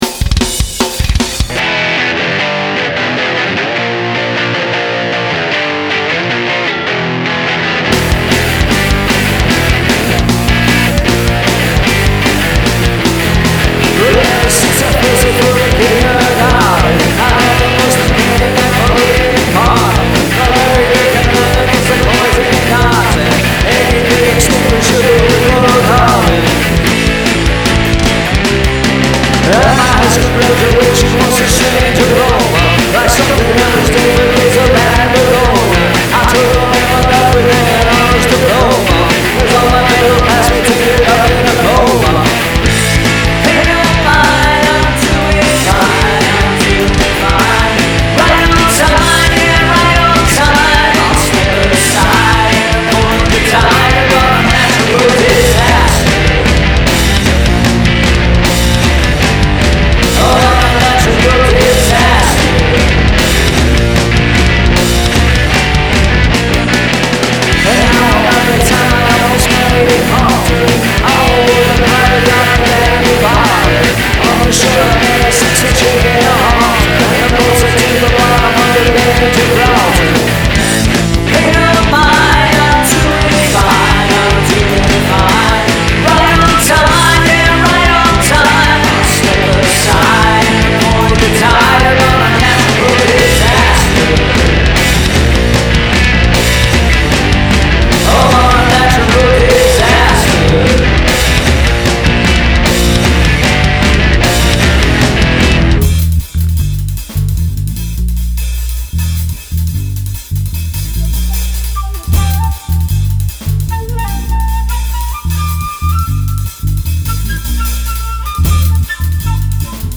Must include prominent use of backwards recording
Killer guitar tone.
Cool wide bass sound in the breakdown, great sounding flute.
Why are the vocals so loud in the last verse?